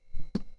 hitFabric3.wav